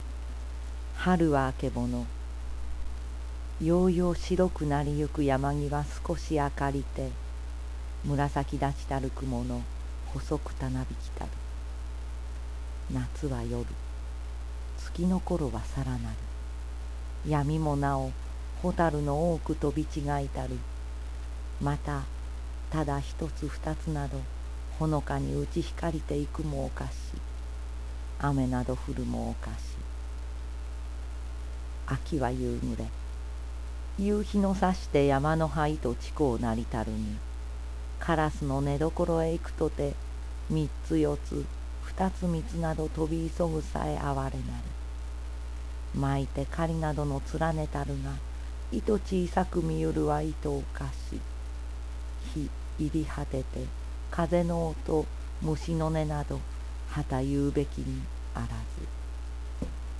今度のはヘッドホンじゃなくて、マイクだけ机に置くヤツ．
まだ、編集までは勉強してないから、サウンドレコーダーで６０秒間の録音なんだけど、これがめっぽう楽しい！
ちょっと聞いてみてください．まぁ、私はダミ声で、しょうがないし、朗読も上手くはありませんけど･･･